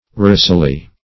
rosily - definition of rosily - synonyms, pronunciation, spelling from Free Dictionary Search Result for " rosily" : The Collaborative International Dictionary of English v.0.48: Rosily \Ros"i*ly\, adv.